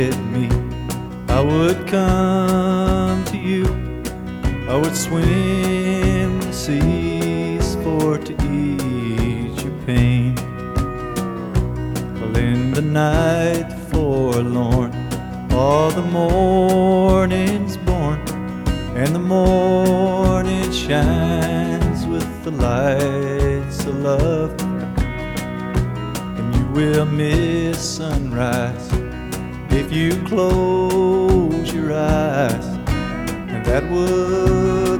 # Кантри